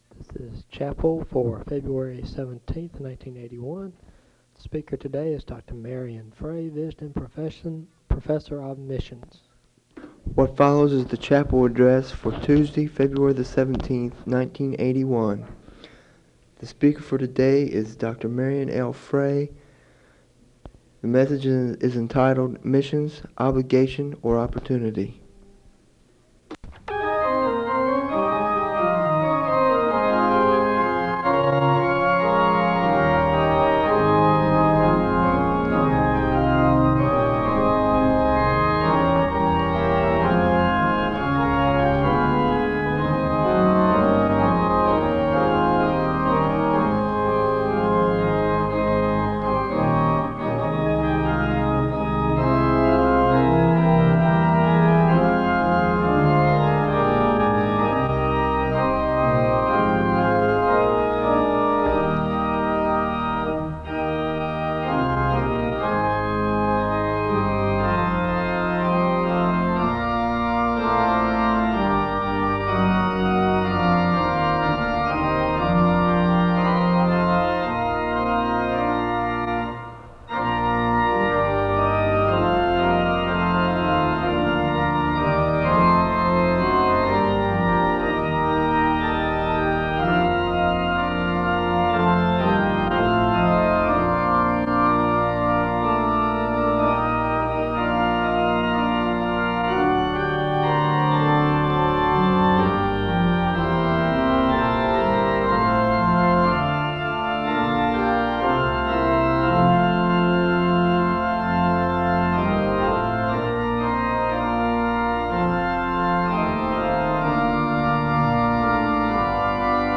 The service begins with organ music (00:00-04:13). A word of prayer is given, and the choir sings a song of worship (04:14-07:40).